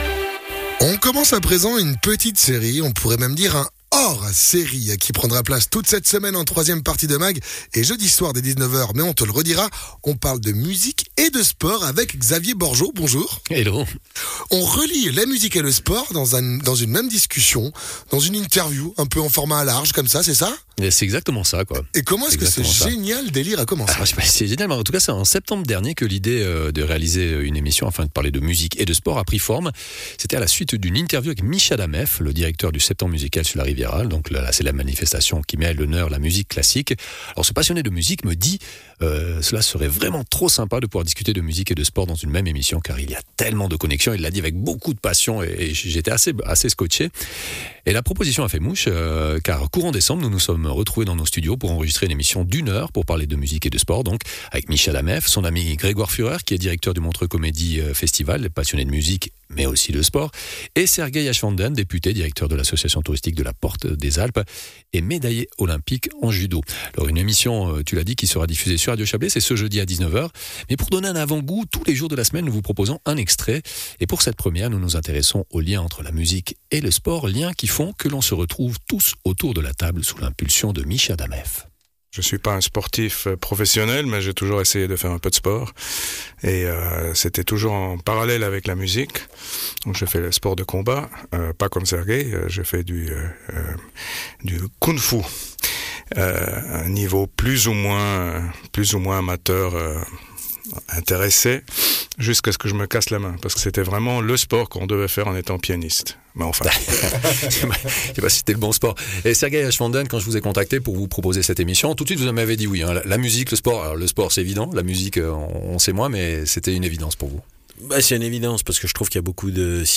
Sergeï Aschwanden, Médaillé Olympique en judo